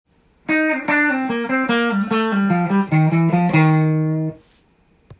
５～８フレット内でメジャーペンタトニックスケールと
マイナーペンタトニックスケールを交互にプリングしながら弾いた